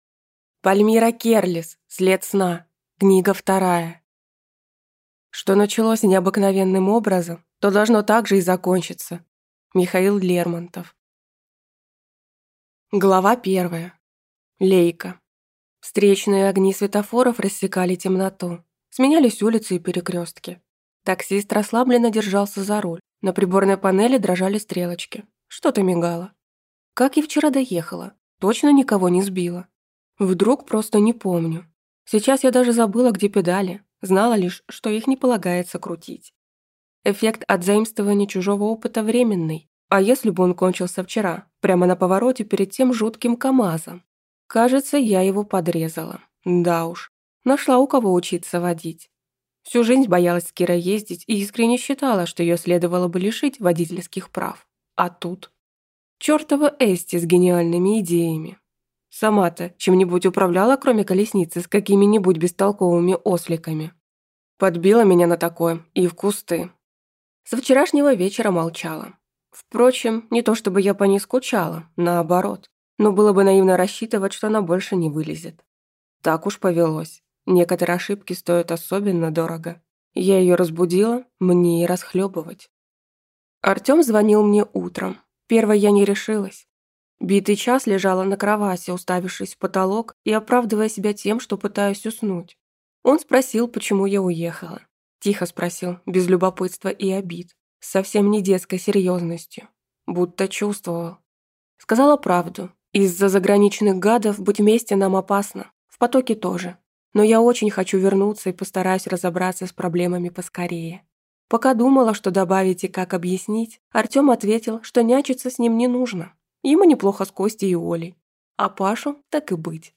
Аудиокнига След сна. Книга 2 | Библиотека аудиокниг